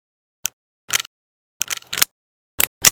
pm_reload_empty.ogg